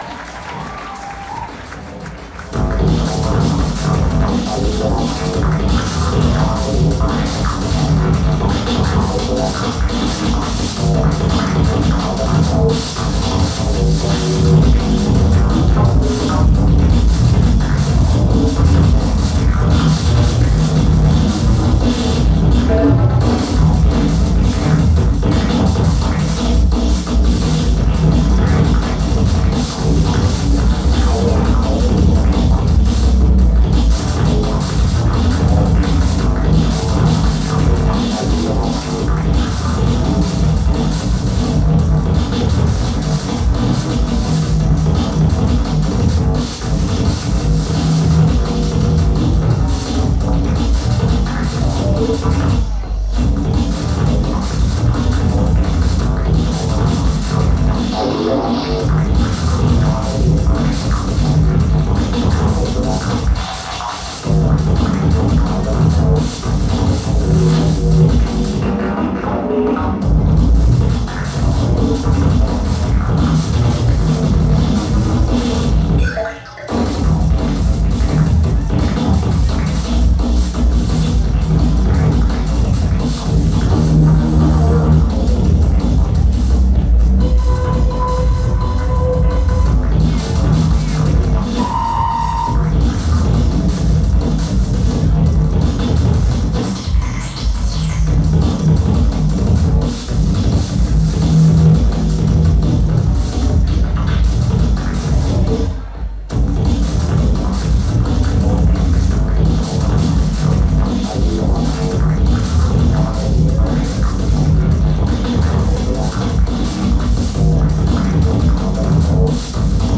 venue Avalon Ballroom